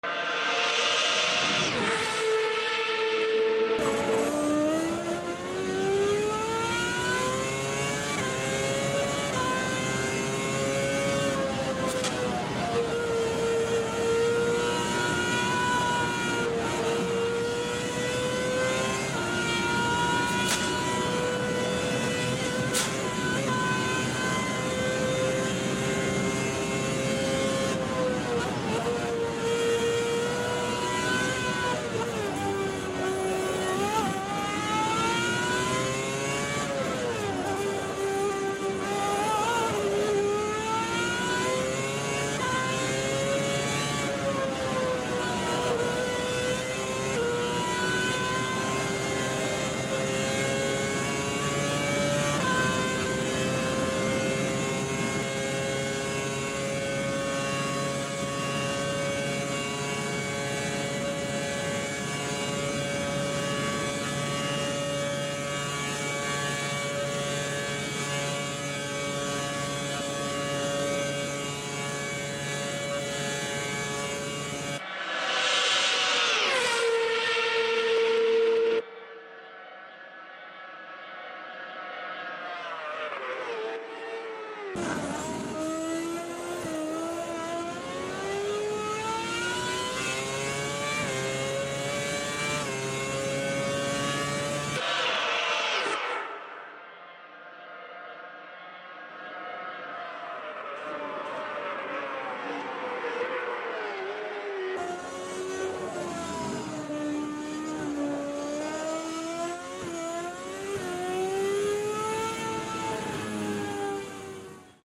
(3/3) Formula 1 V8 At Sound Effects Free Download